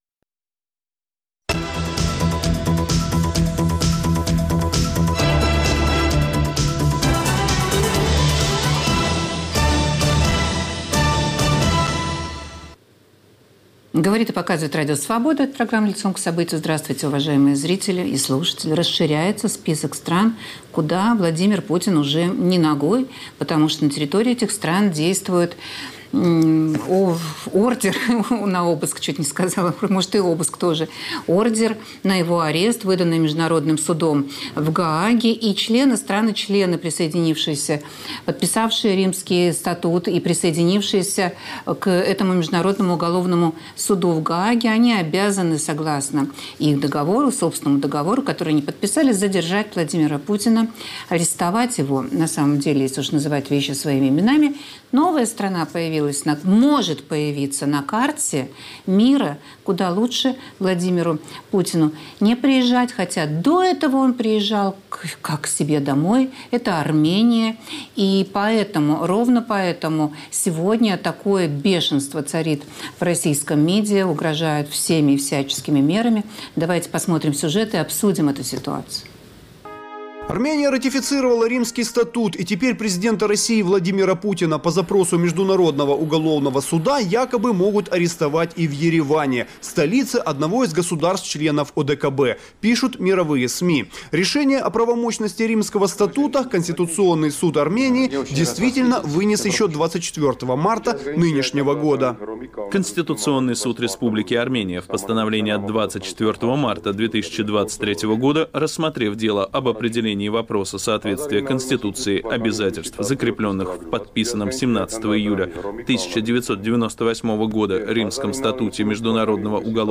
В эфире политолог